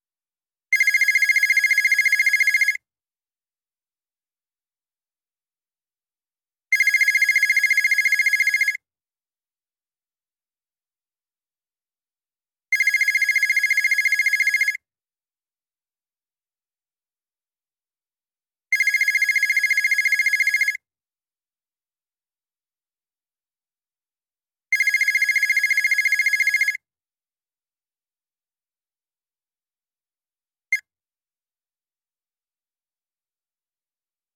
Звуки звонков
Звук звонка мобильного телефона со стандартной мелодией